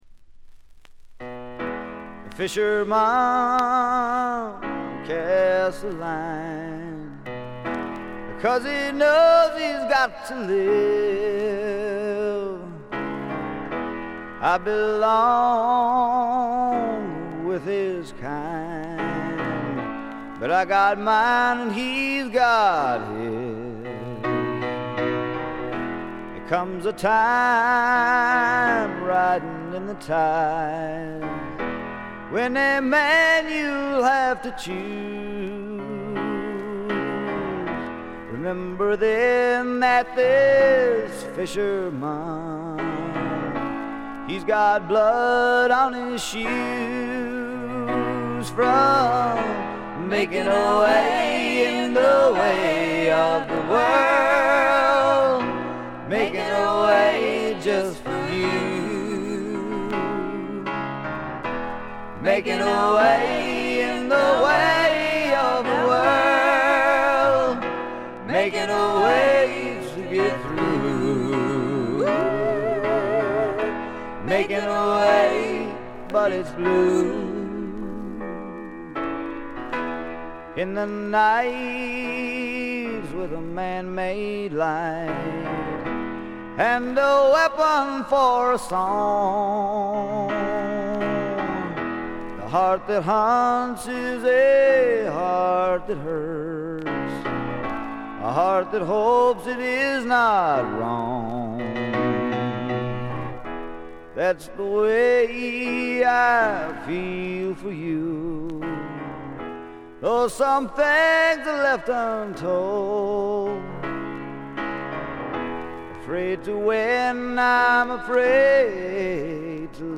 ごくわずかなノイズ感のみ。
聴くものの心をわしづかみにするような渋みのある深いヴォーカルは一度聴いたら忘れられません。
試聴曲は現品からの取り込み音源です。